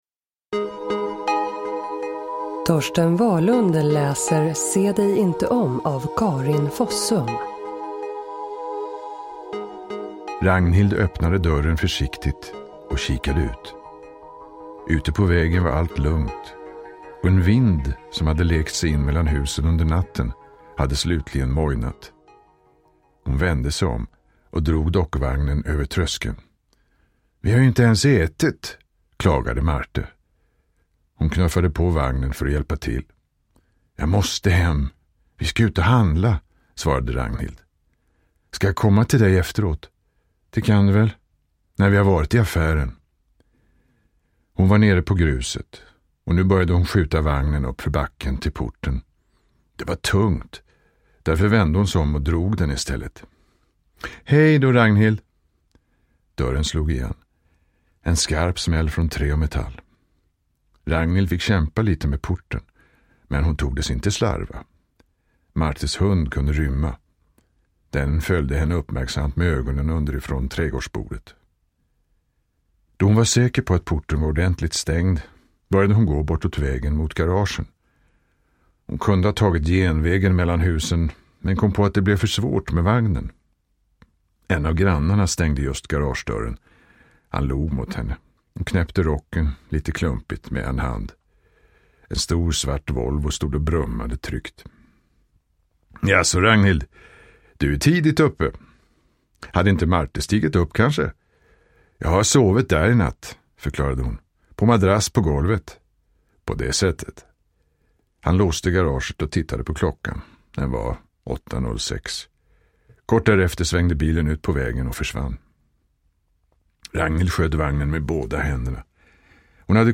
Uppläsare: Torsten Wahlund